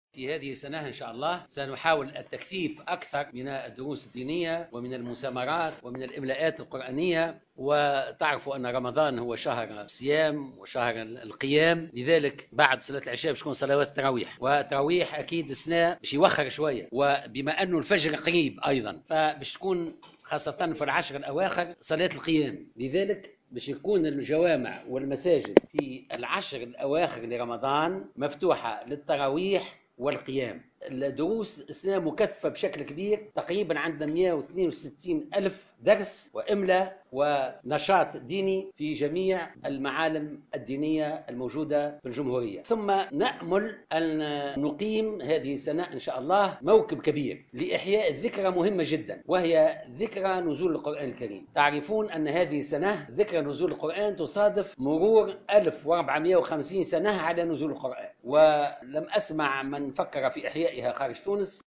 أكد وزير الشؤون الدينية محمد خليل خلال ندوة صحفية عقدت اليوم الأربعاء 1 جوان 2016 استعداد وزارته لاستقبال شهر رمضان المعظم في ظروف طيبة .